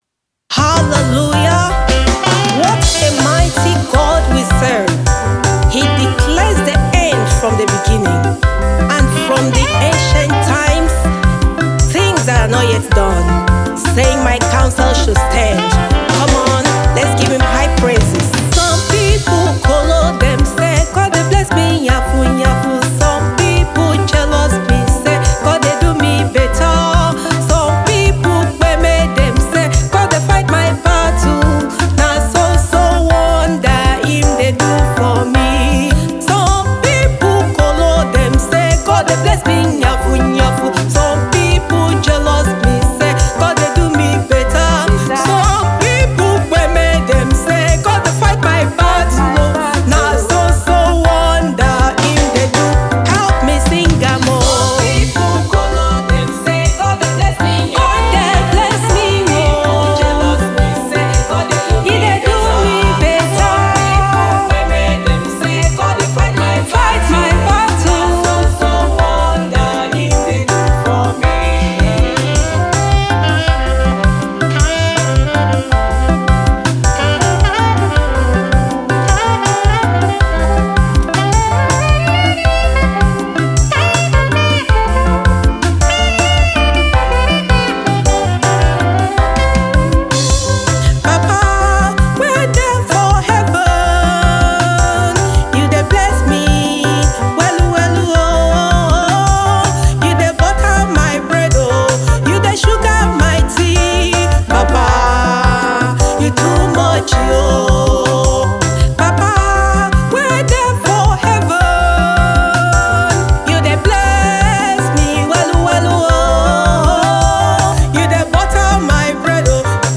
Gospel Music
It sets the tone for electrifying high praise.